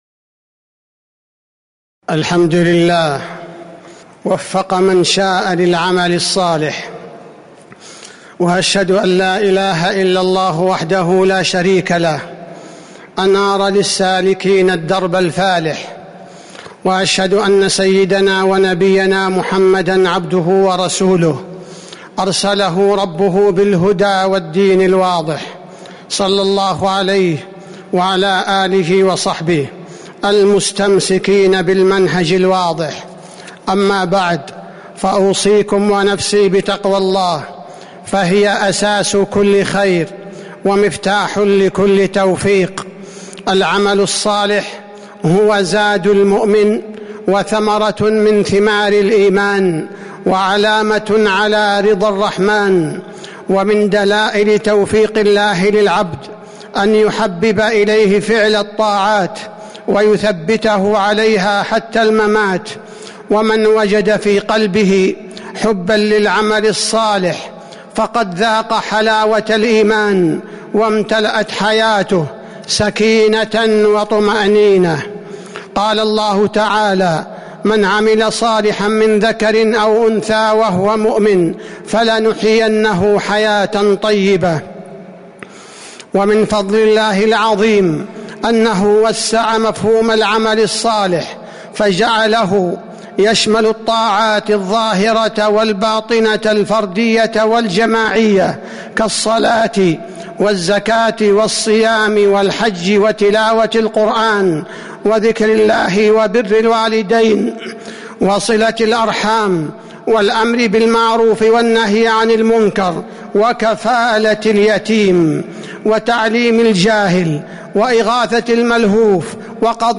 تاريخ النشر ١٠ ذو الحجة ١٤٤٦ هـ المكان: المسجد النبوي الشيخ: فضيلة الشيخ عبدالباري الثبيتي فضيلة الشيخ عبدالباري الثبيتي العمل الصالح